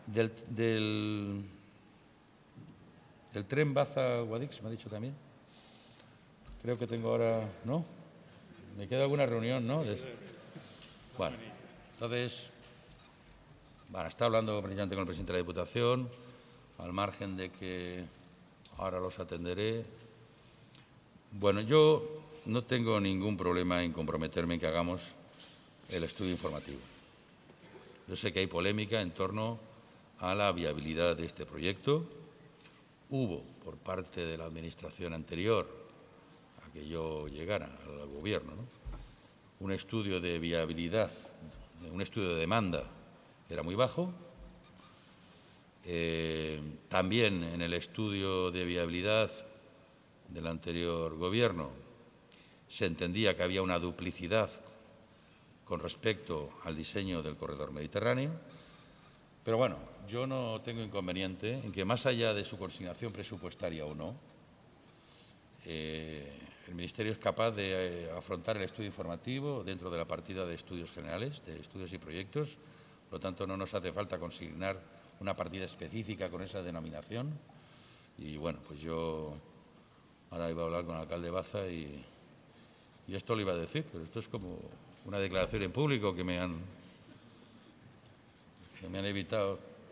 A preguntas de los periodistas, Ábalos ha analizado algunas de las demandas en materia de infraestructura de la provincia de Granada y ha adelantado que ADIF acaba de aprobar la adjudicación de las instalaciones de seguridad del cambiador de ancho de Granada.